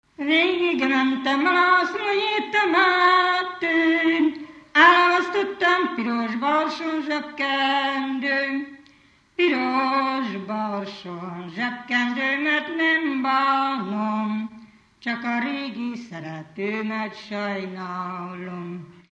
Dunántúl - Verőce vm. - Szentlászló
ének
Stílus: 1.1. Ereszkedő kvintváltó pentaton dallamok
Kadencia: 7 (5) b3 1